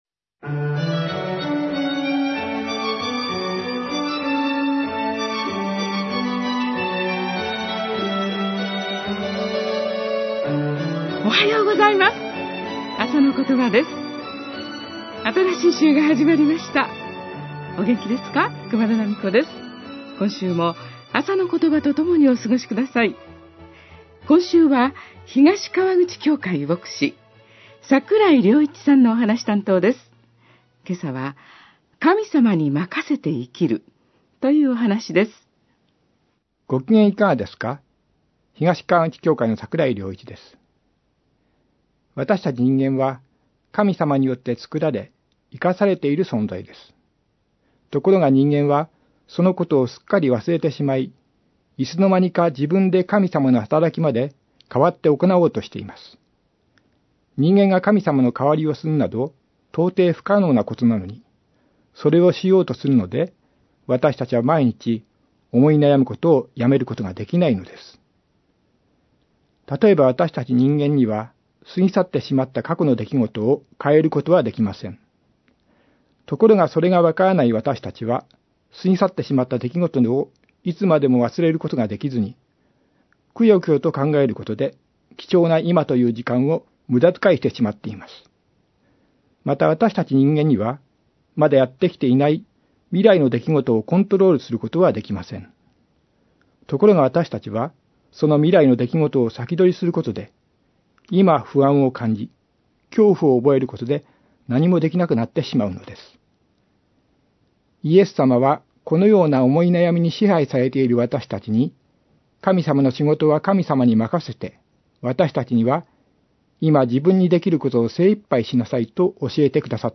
メッセージ： 神様に任せて生きる